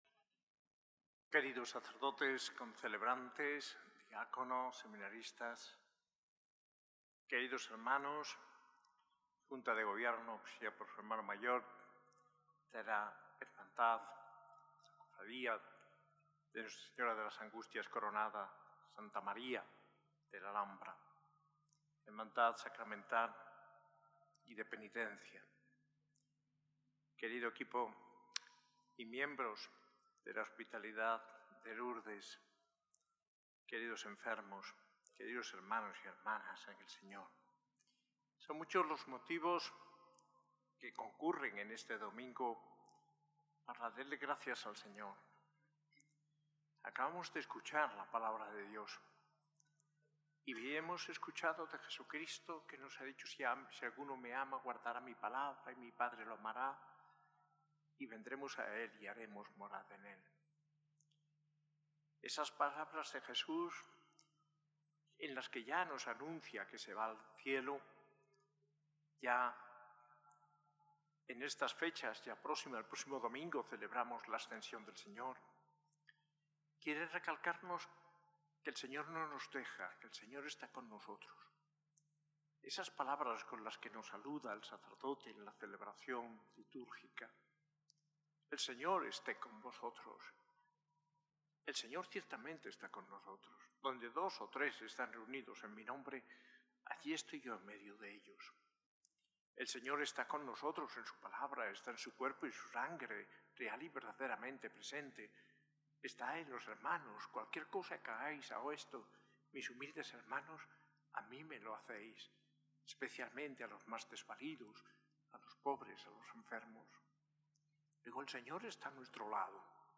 Homilía en la Eucaristía del VI Domingo del Tiempo Pascual de manos del arzobispo Mons. José María Gil Tamayo, celebrada en la S.A.I Catedral el 25 de mayo de 2025.